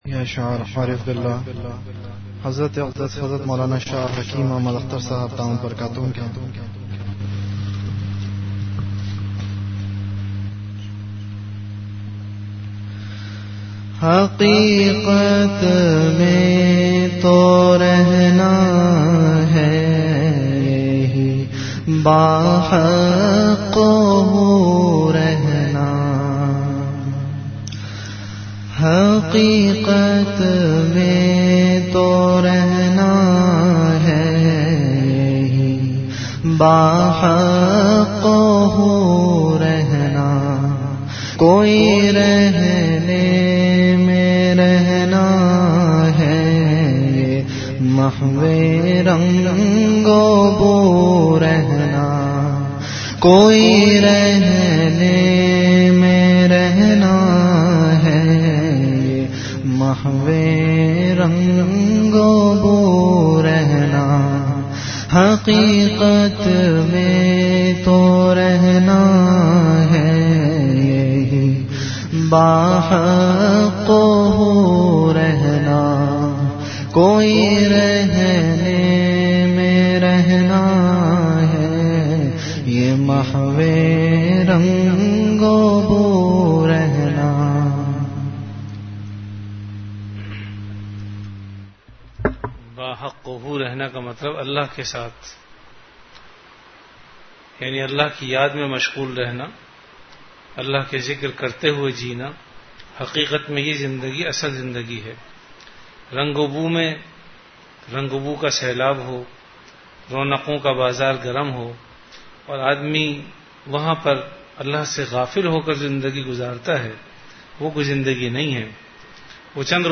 An Islamic audio bayan
Delivered at Home.